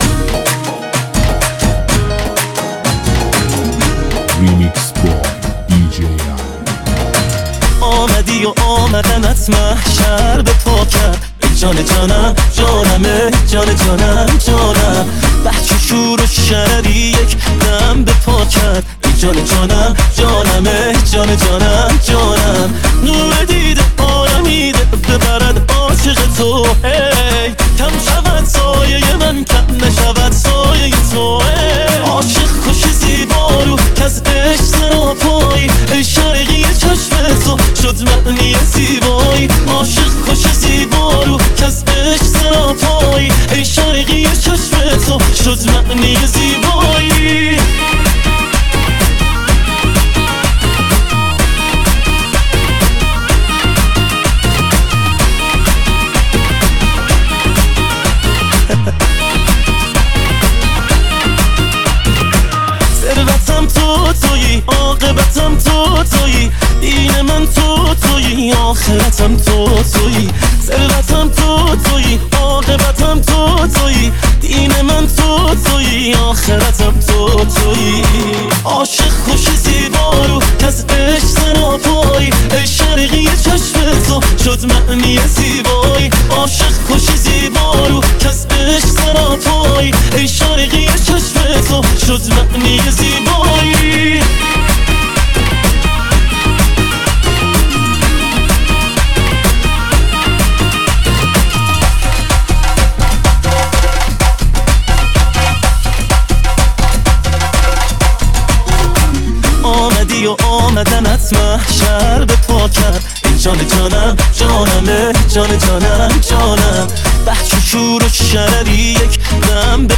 موسیقی شاد و پرانرژی برای شروع بهتر روزهای خود.